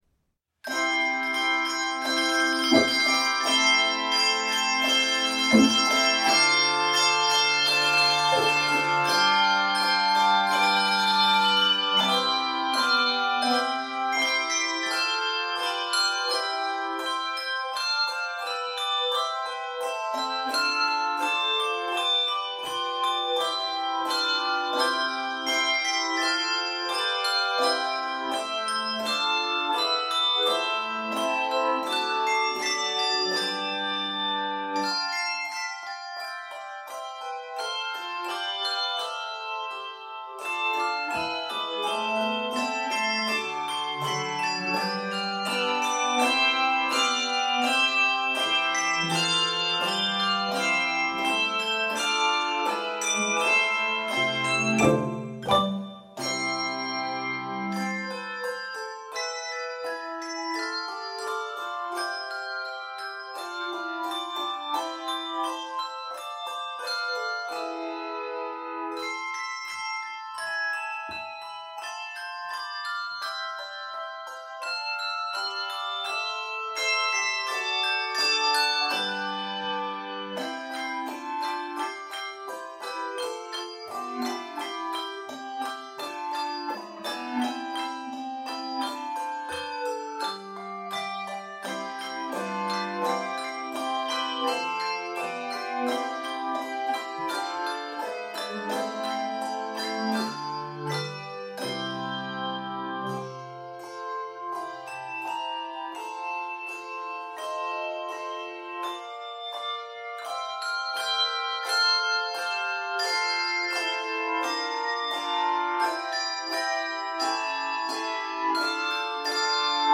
written in C Major and F Major